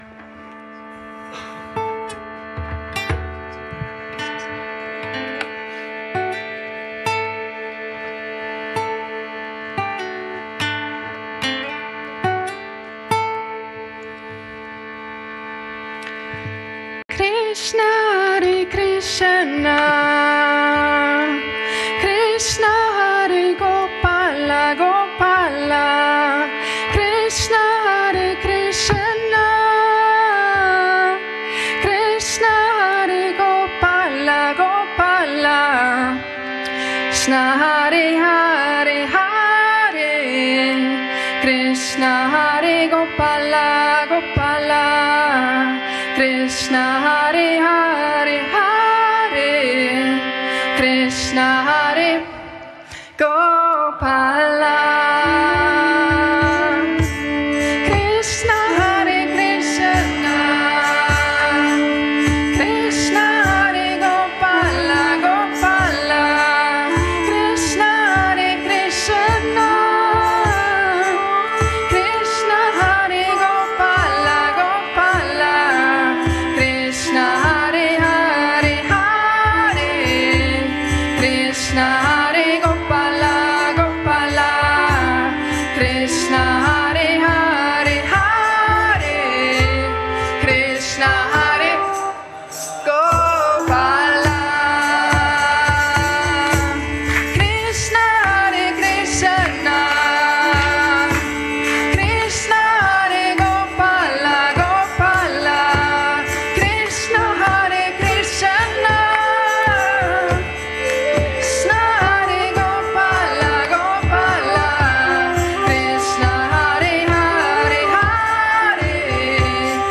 Yogalehrer singen den Kirtan Krishna Hare Krishna Gopala in einem
Samstagabend Satsang bei Yoga Vidya in Bad Meinberg.
wiederholt gesungen — oft im Wechsel zwischen Vorsänger*in und Chor
(Call-and-Response), begleitet vom Harmonium.
Eintreten und Mitverbinden. Dann zunehmend rhythmisch und expressiv
Schließlich in einen meditativen, getragenen Klangraum, der Raum